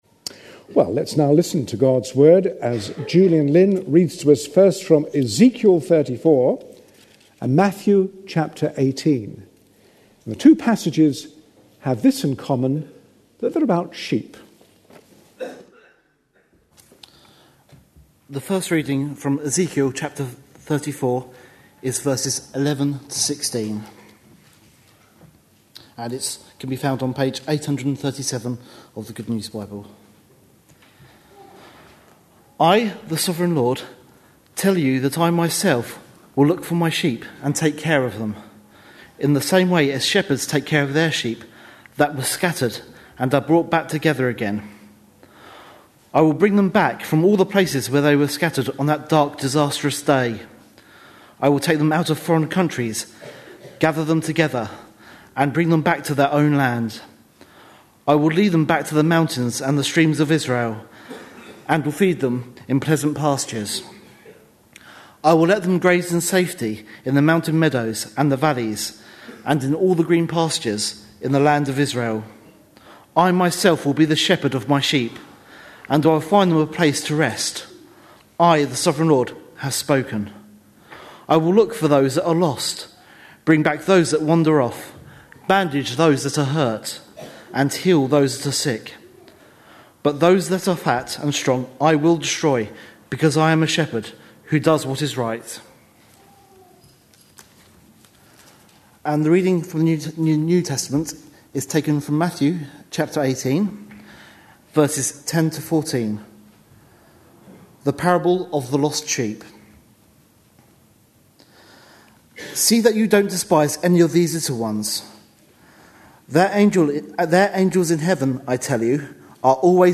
A sermon preached on 3rd October, 2010, as part of our Parables of Matthew series.